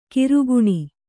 ♪ kiruguṇi